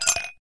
lou_reload_01.ogg